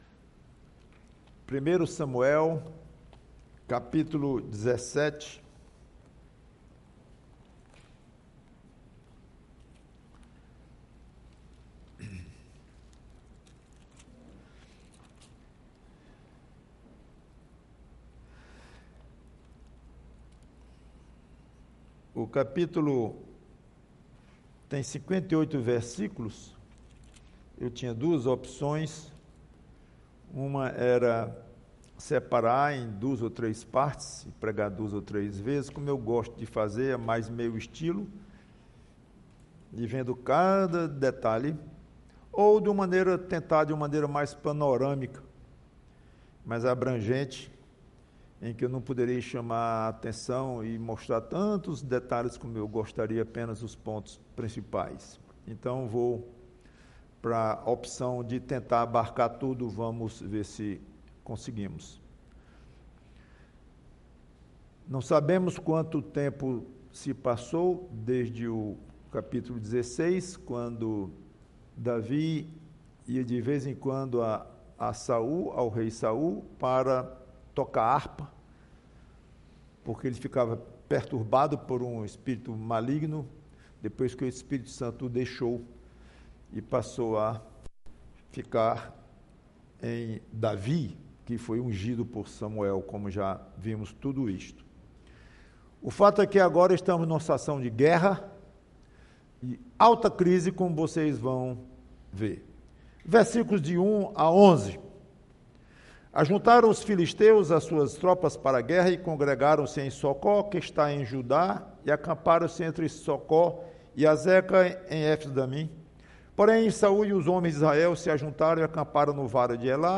PREGAÇÃO Davi contra Golias: isso é que é zelo!